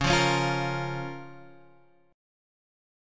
Db6 chord